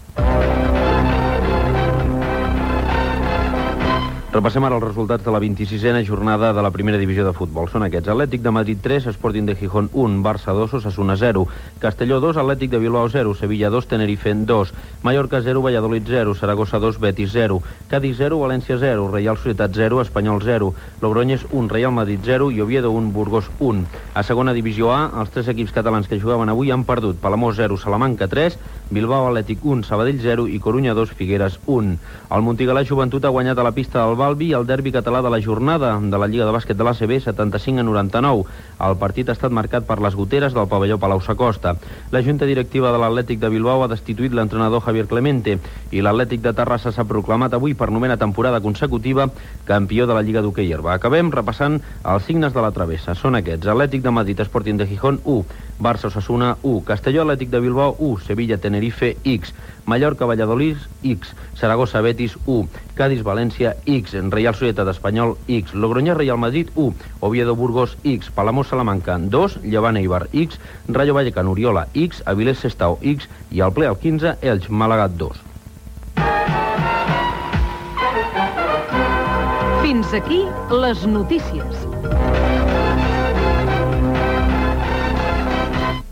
Separador musical, resultats de la 26ena jornada de primera divisió de futbol, travessa, careta de sortida de l'informatiu.
Informatiu